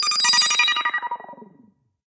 synth_chime.ogg